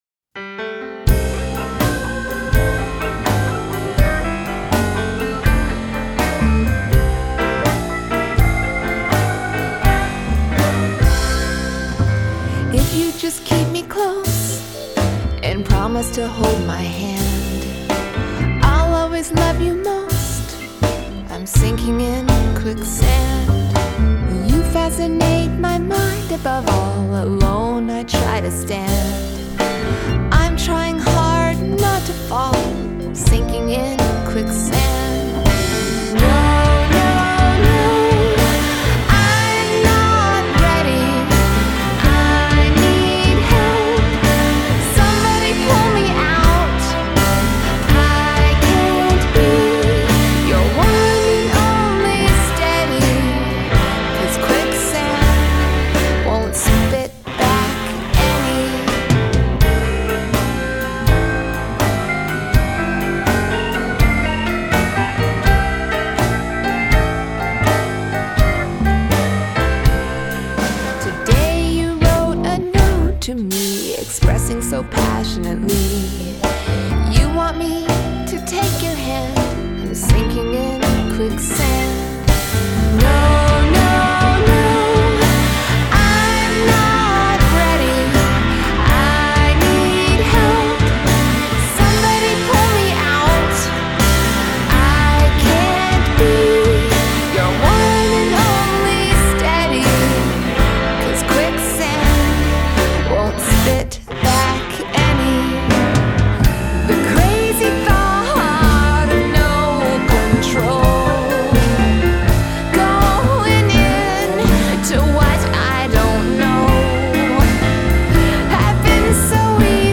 Adult Contemporary
Indie Pop , Musical Theatre
Soft Rock